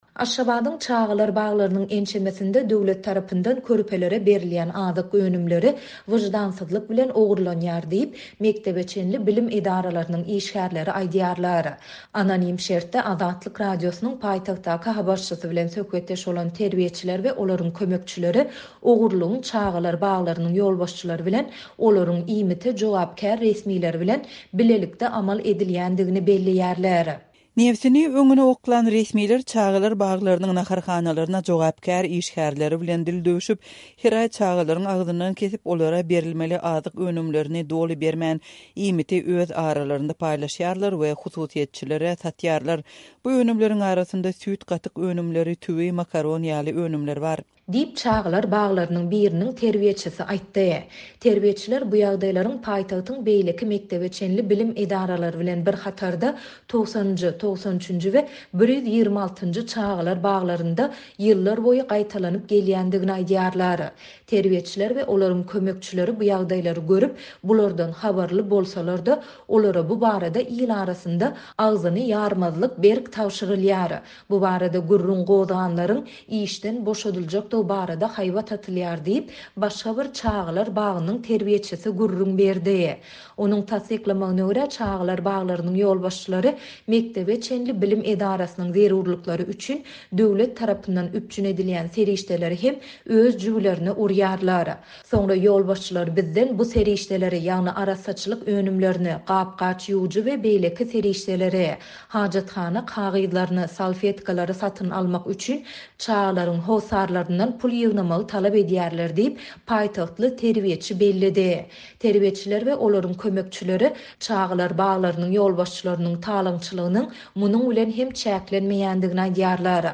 Aşgabadyň çagalar baglarynyň ençemesinde döwlet tarapyndan körpelere berilýän azyk önümleri wyždansyzlyk bilen ogurlanýar diýip, mekdebe çenli bilim edaralarynyň işgärleri aýdýarlar. Bu barada Azatlyk Radiosynyň habarçysy paýtagtdan maglumat berdi.